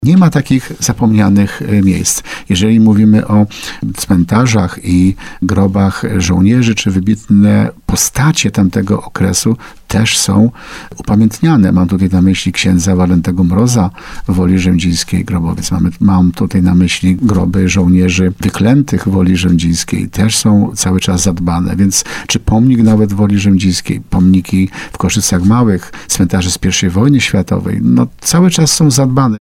Nie ma w naszej gminie miejsc zapomnianych, wszyscy pamiętamy o bohaterach walczących o wolną Polskę – przekonuje wójt gminy Tarnów.